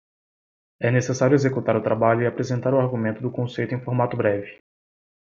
Read more concept Frequency C1 Pronounced as (IPA) /kõˈsej.tu/ Etymology Inherited from Latin conceptus In summary From Latin conceptus.